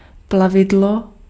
Ääntäminen
Vaihtoehtoiset kirjoitusmuodot (vanhentunut) crafte Synonyymit cunning guile workmanship craftsmanship slyness craftiness foxiness wiliness trade art boat engender Ääntäminen US : IPA : /kɹæft/ RP : IPA : /kɹɑːft/